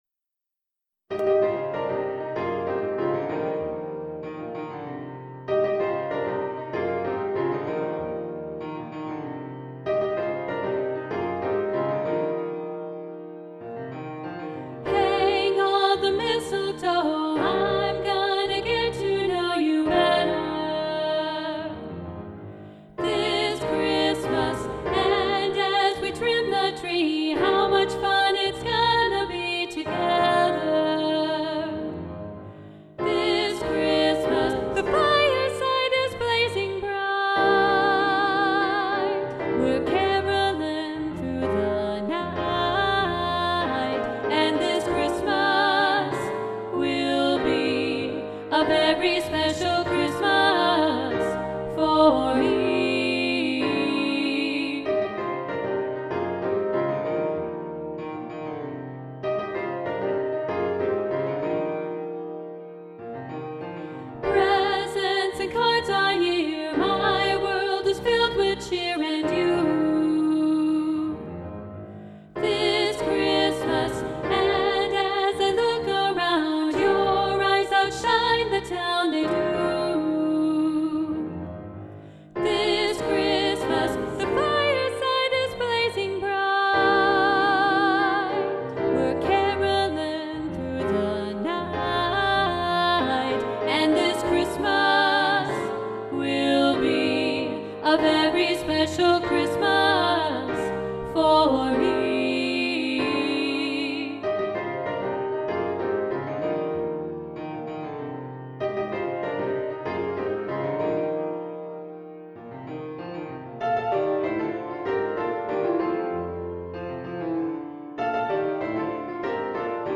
This Christmas SSA – Soprano 1 Muted – arr. Roger Emerson